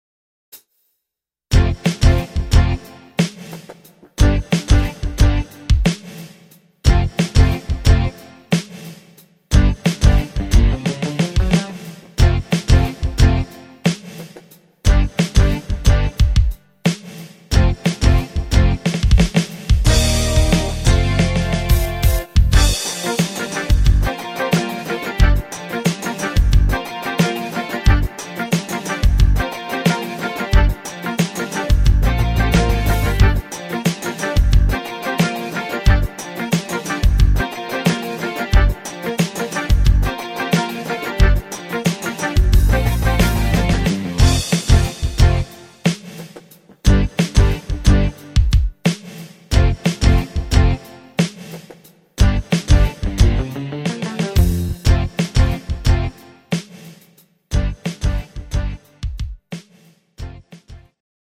Rhythmus  Rock